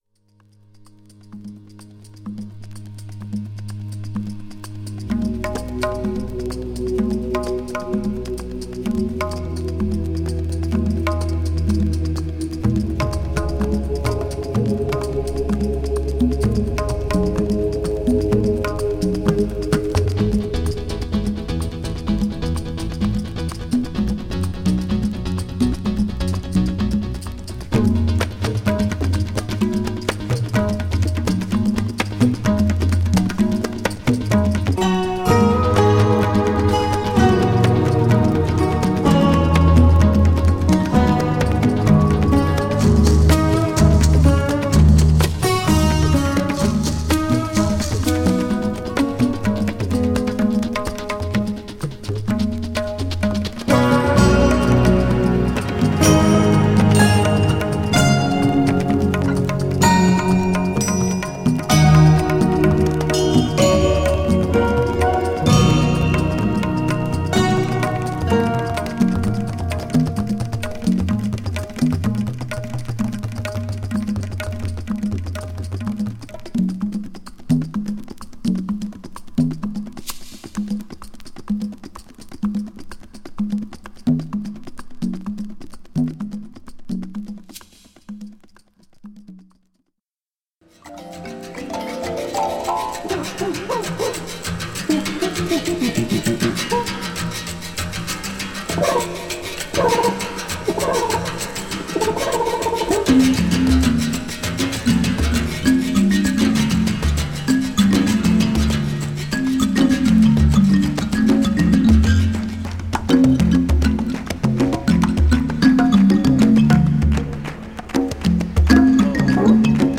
JAZZ
細かな擦りの為、チリチリ個所あります。